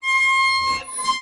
gate.ogg